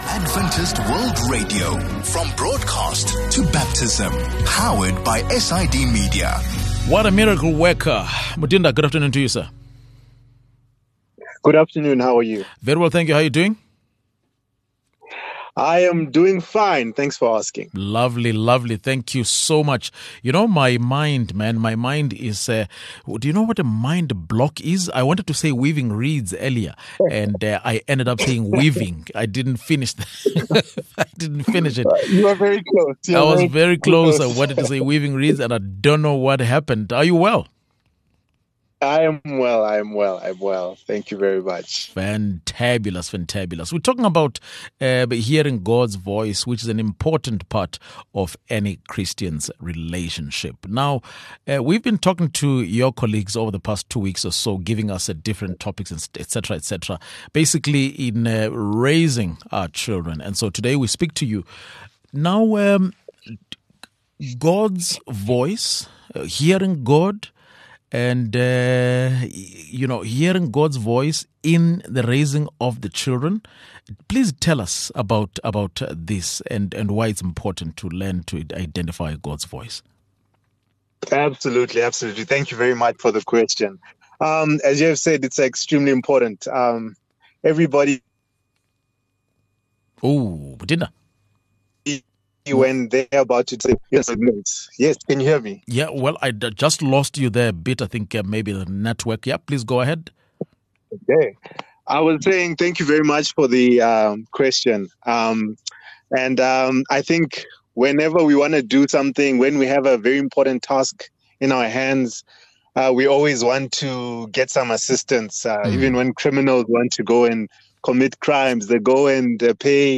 Intro: In our conversation with the Weaving Reeds team this afternoon, we’re talking about hearing God’s voice, an important part of any Christian’s relationship.